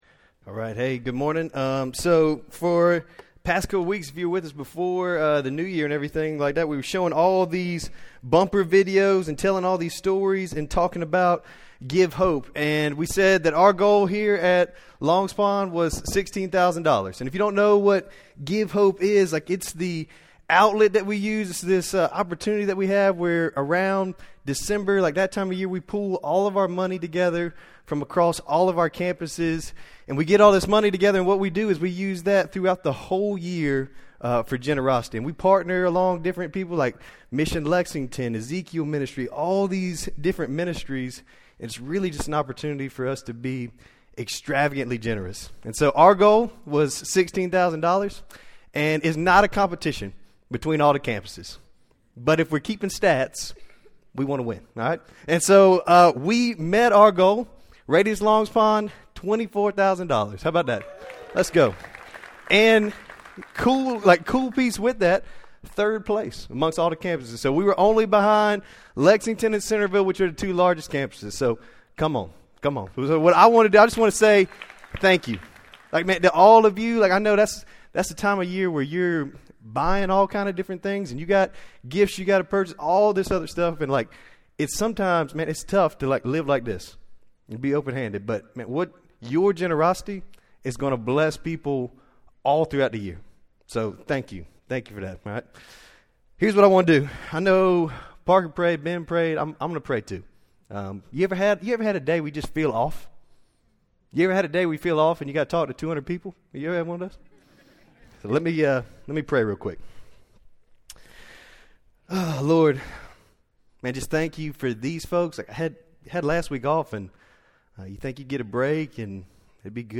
Sermon Library | RADIUS Church